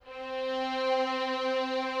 Added more instrument wavs
strings_048.wav